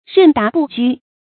任达不拘 rèn láo bù jū
任达不拘发音
成语注音 ㄖㄣˋ ㄉㄚˊ ㄅㄨˋ ㄐㄨ